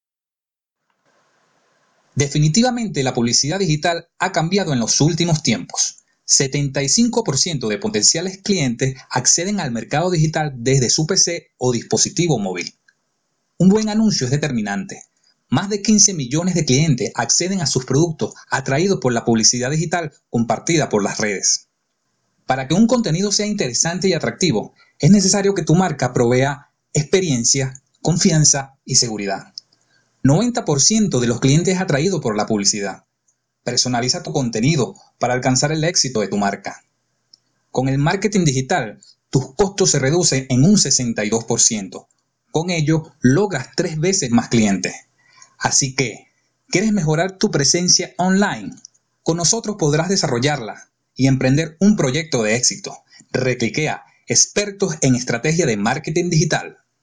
Voz versatil y adaptable en velocidad y tono
spanisch Südamerika
Sprechprobe: Industrie (Muttersprache):
Narracion.mp3